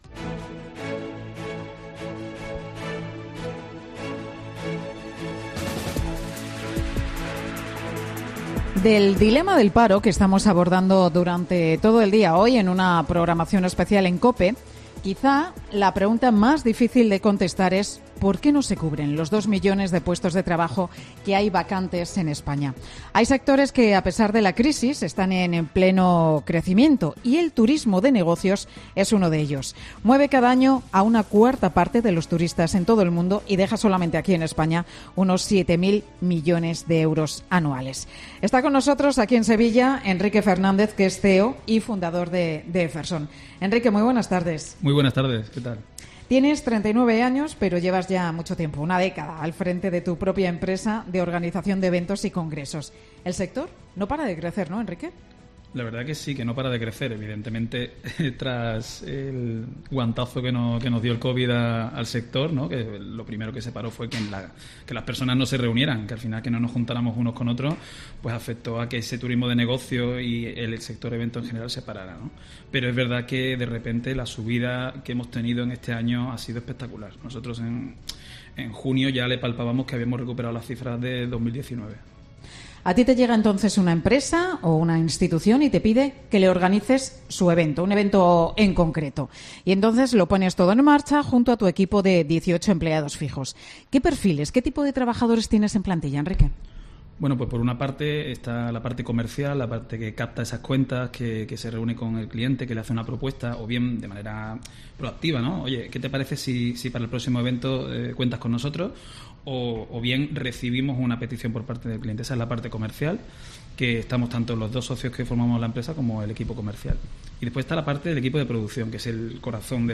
Mediodía COPE, con Pilar García-Muñiz, se ha trasladado a Sevilla